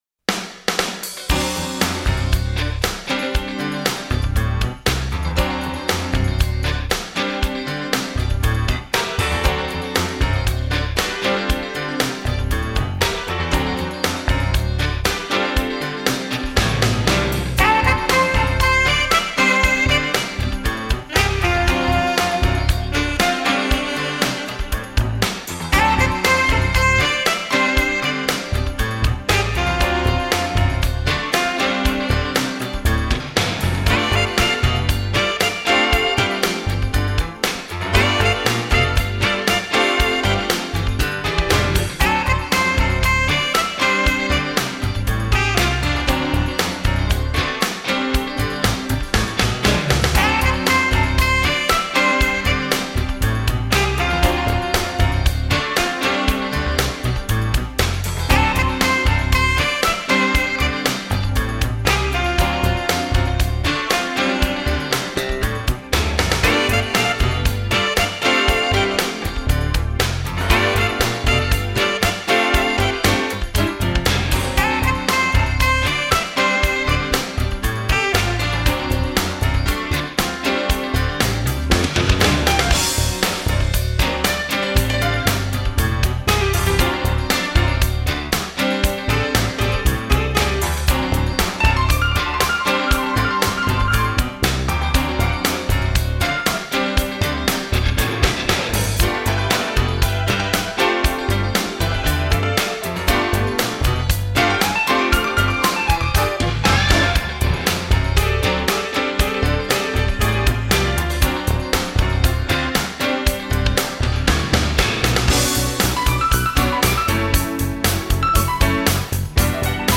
upbeat, funky instrumental
keyboards